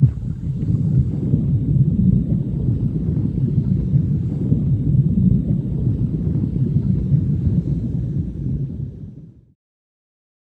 Index of /90_sSampleCDs/E-MU Producer Series Vol. 3 – Hollywood Sound Effects/Water/Scuba Breathing